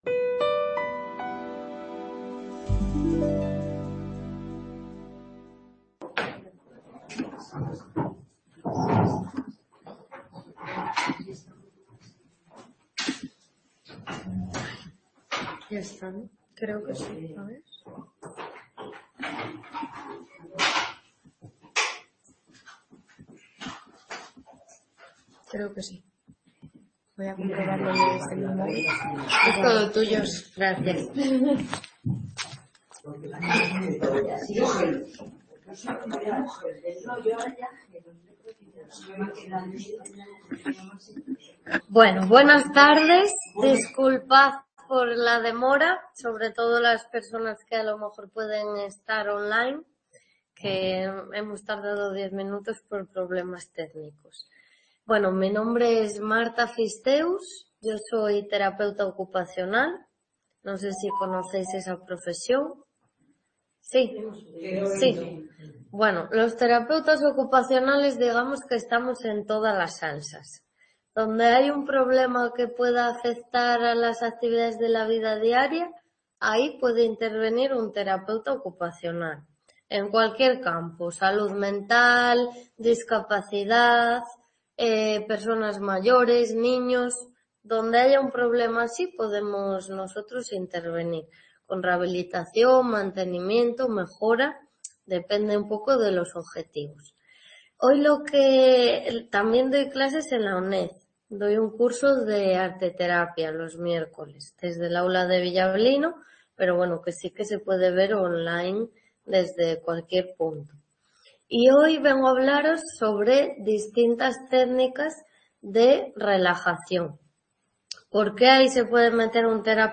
Conferencias y talleres